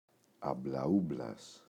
αμπλαούμπλας, ο [ablaꞋublas]